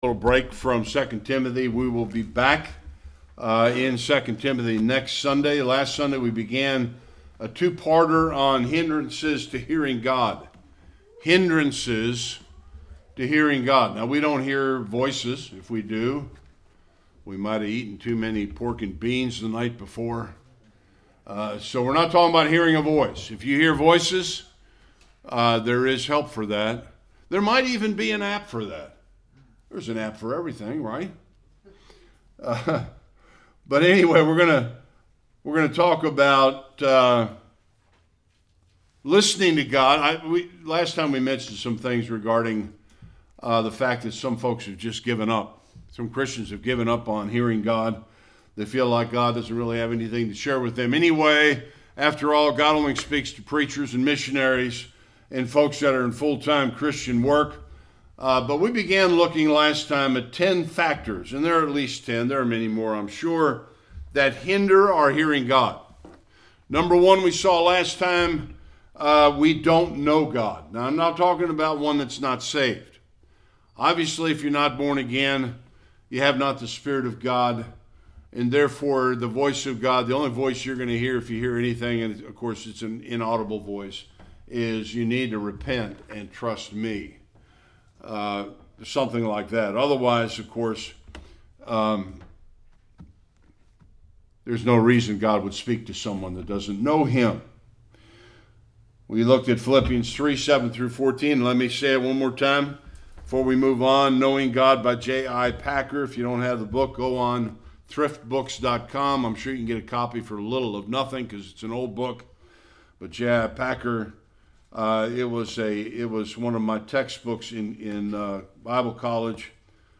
Various Passages Service Type: Sunday Worship The last of 7 things that will hinder our hearing God.